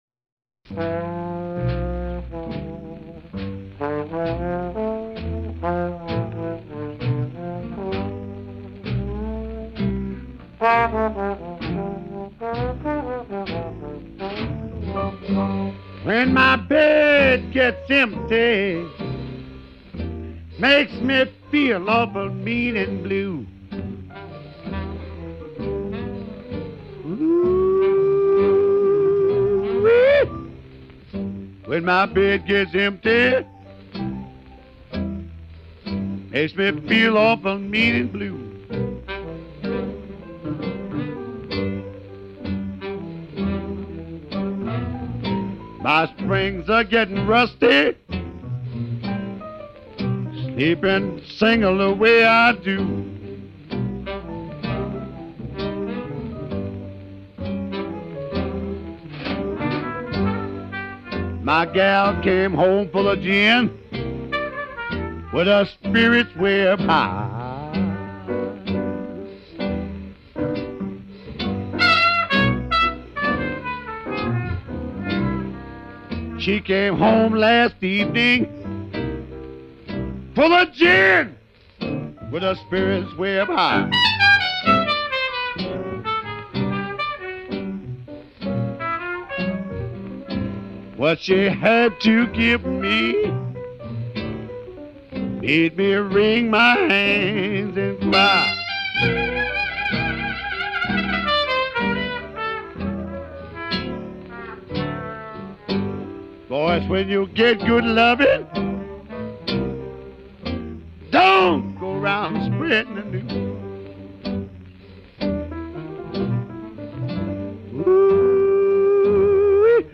as blues & jazz singer is more correct
More Music...all as featured vocalist